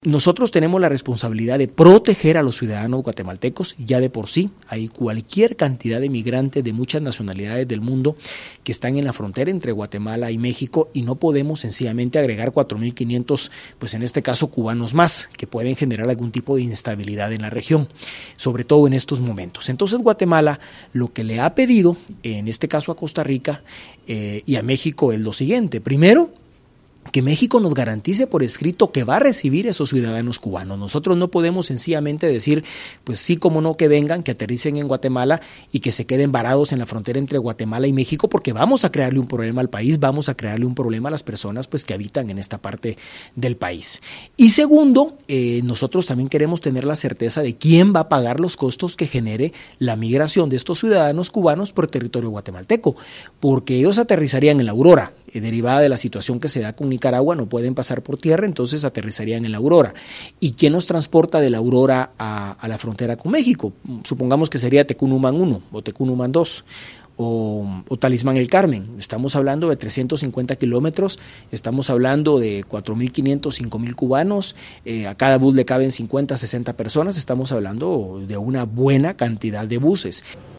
En entrevista con el canal centroamericano de noticias CB24, el canciller Carlos Raúl Morales dijo: